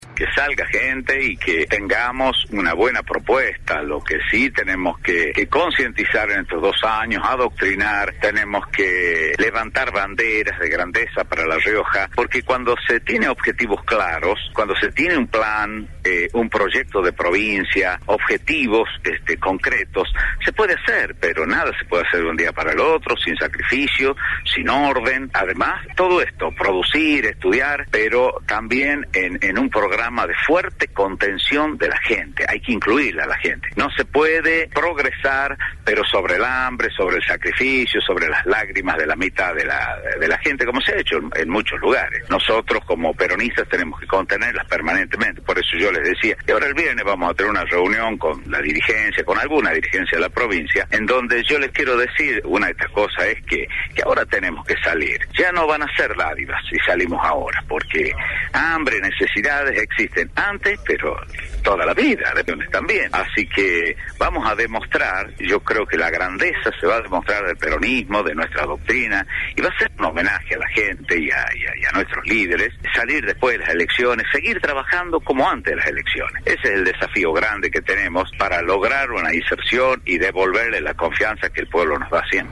En diálogo con Radio Fénix, el gobernador se refirió a diferentes temas, entre ellos, la entrega de dádivas que repercutió a nivel nacional, sosteniendo que se reunirá con la dirigencia el viernes.